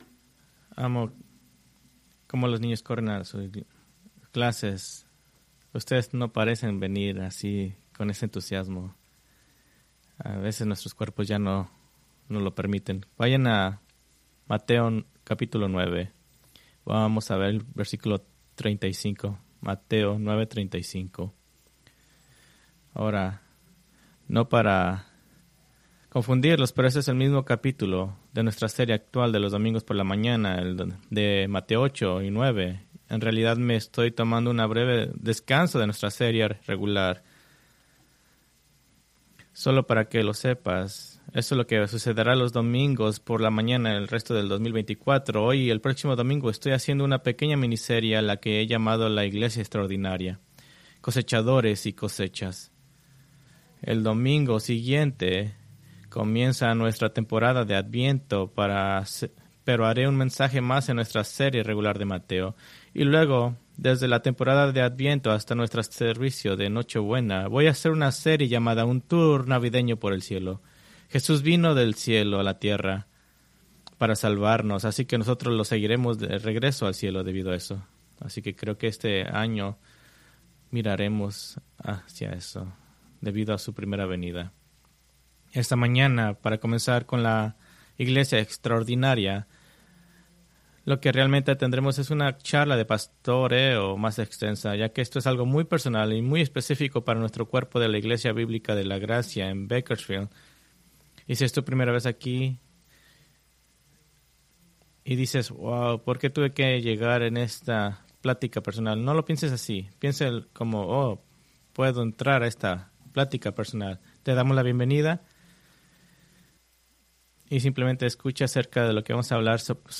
Preached November 17, 2024 from Escrituras seleccionadas